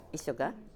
Type: Yes/no question
Final intonation: Rising
Location: Showamura/昭和村
Sex: Female